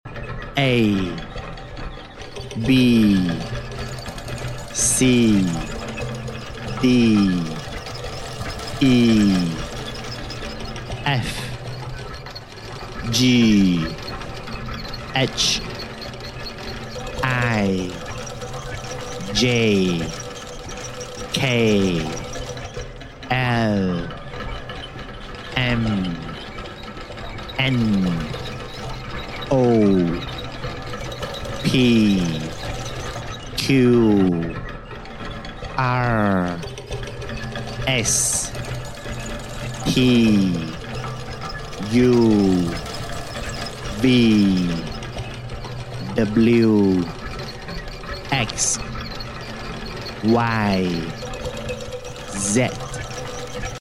Metal letter with gears mechanism